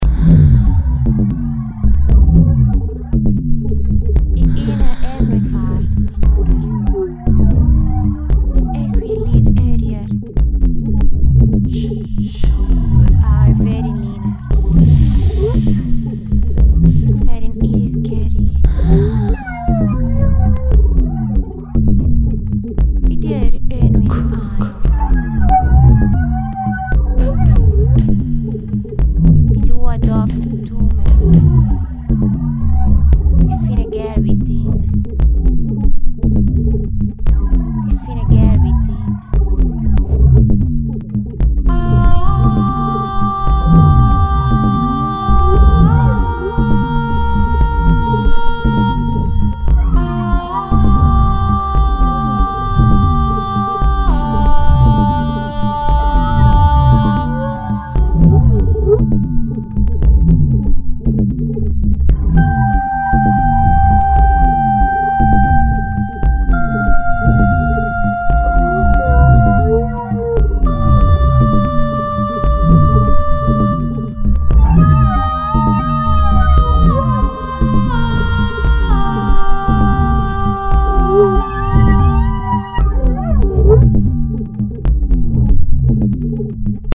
Ist das nicht die perfekte Kulisse für Elfenstimmen?